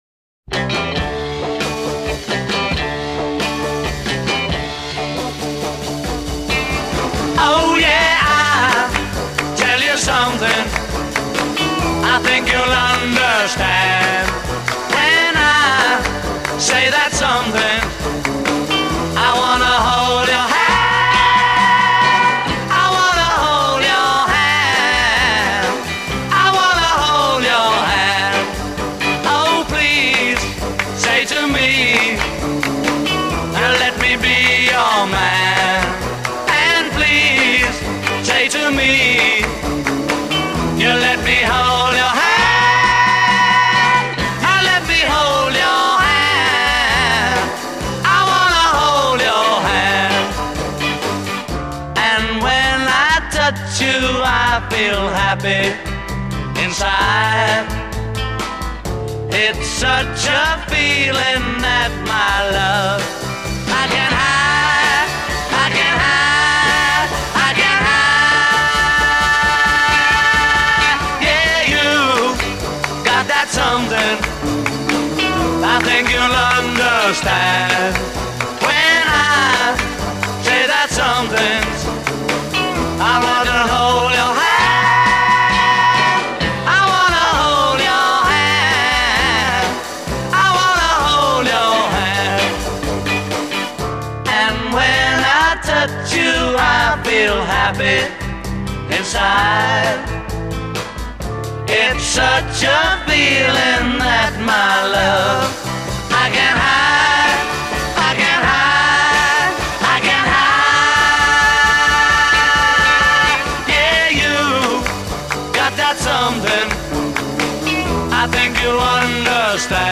voice & rhythm guitar
voice & bass guitar
lead guitar
drums
Intro 0:00 81/2 Ensemble begins on off beat
A verse 0: 16 unison with harmony on song title a